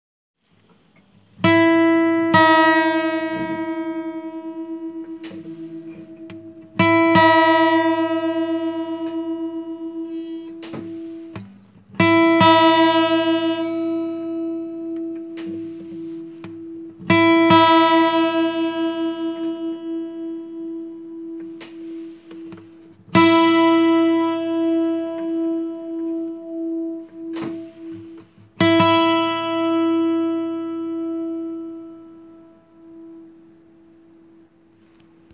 2. If you are new to tuning you might want to avoid that, and instead continue with the method we have been using: place your finger on the 5th fret of the second (B) string, and you will play an E which exactly matches your open first string.
High E String being tuned
E-string.mp3